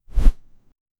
Some one saying "wow" in front of a tv set 0:08 childs footsteps, bare feet, on hardwood flooring 0:15 The sound of a blanket being rolled up. Suitable for a casual mobile game. Clean, crisp, slightly cartoony, with a smooth fade-out. 0:01
the-sound-of-a-blanket-na6ia4lw.wav